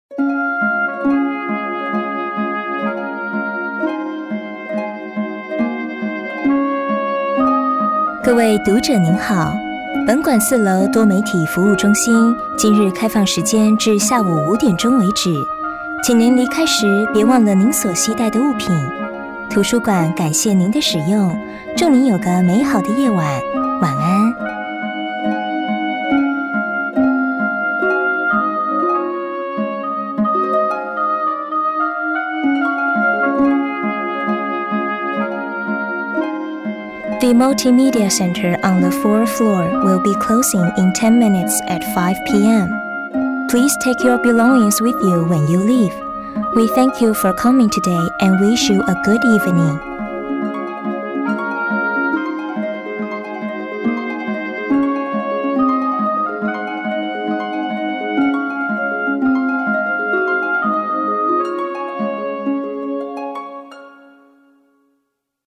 台語配音 國語配音 女性配音員
【NTU圖書館】中英文廣播
【NTU圖書館】中英文廣播.mp3